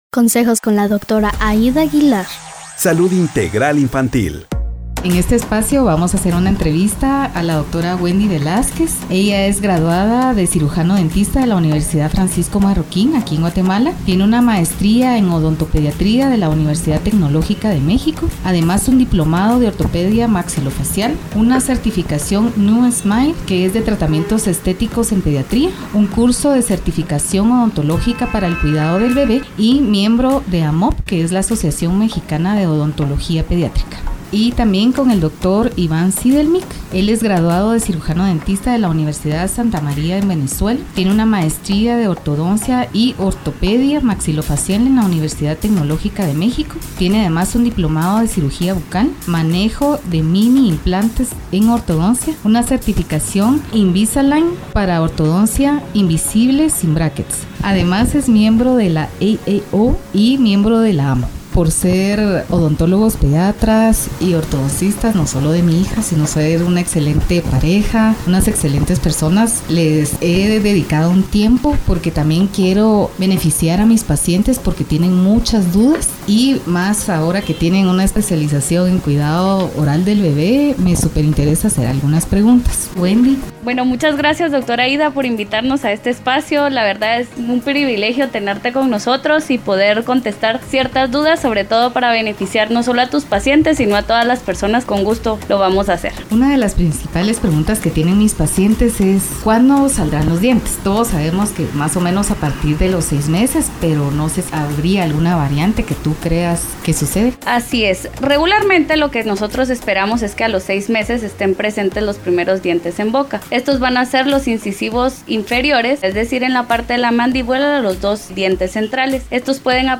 Odontología Pediátrica. Todo lo que los padres quieren saber. Entrevista